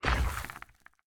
sounds / mob / warden / step_2.ogg
step_2.ogg